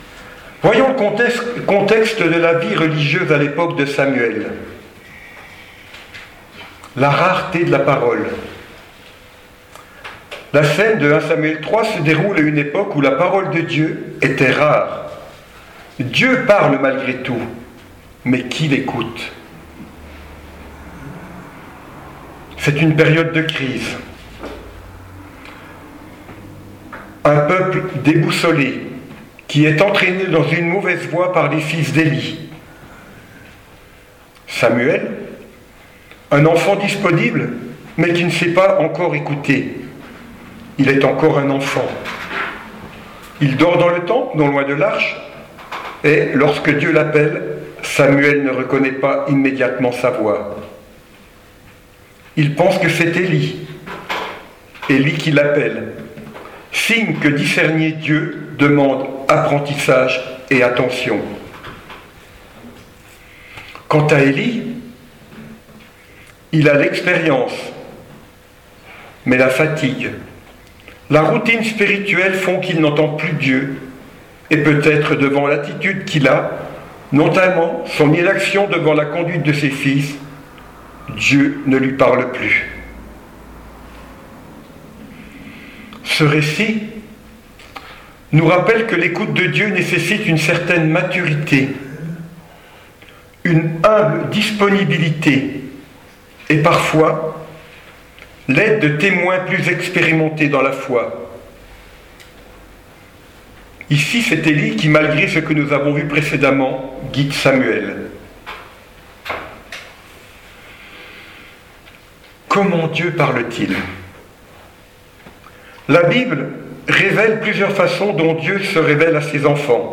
Messages audio 2025
Église Mennonite dans les Vosges